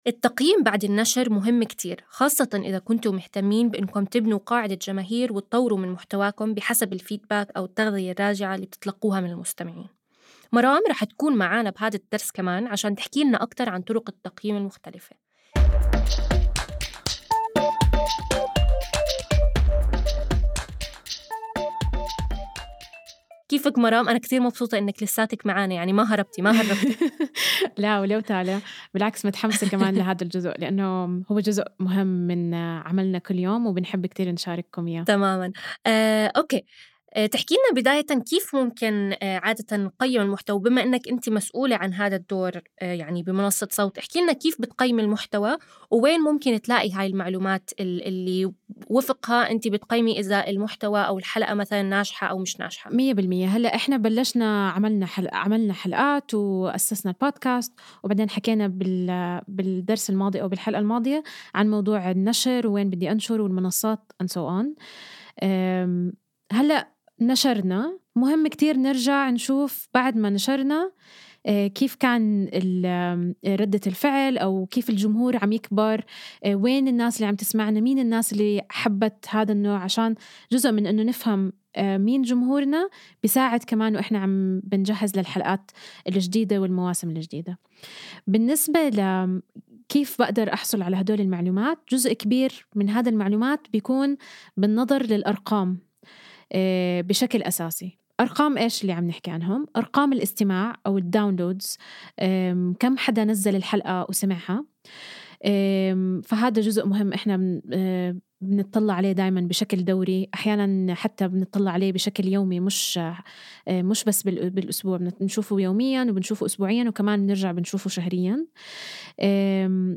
ابتداءً من الحلقة ١٤ وحتى نهاية المساق لن تجدوا تفريغ لهذه الحلقات حيث ستكون الحلقات حوارية وتكمن قيمتها في الاستماع لها.